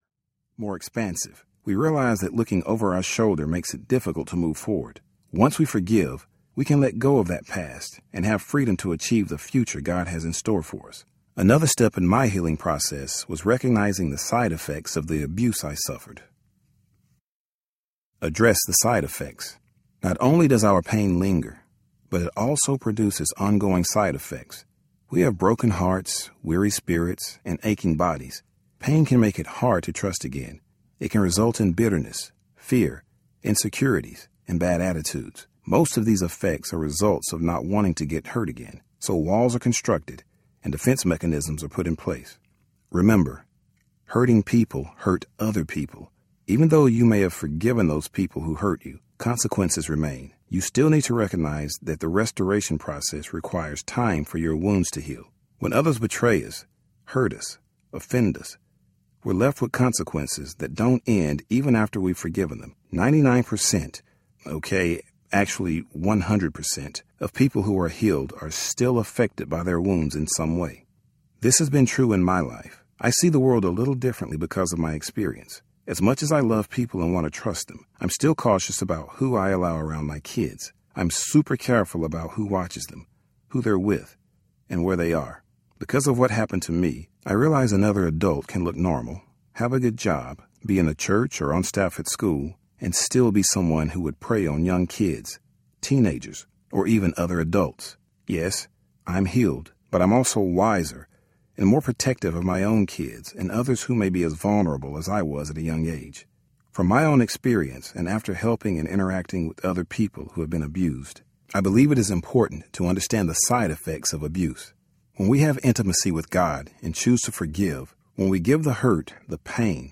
But God Audiobook
Narrator